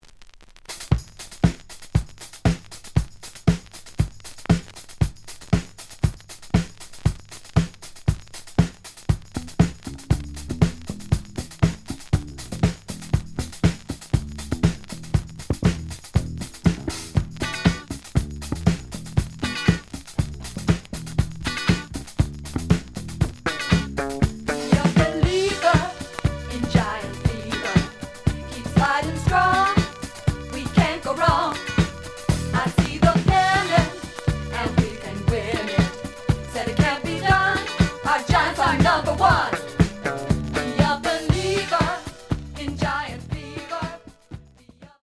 The audio sample is recorded from the actual item.
●Genre: Disco
Looks good, but slight noise on both sides.)